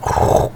move_rock.mp3.wav.mp3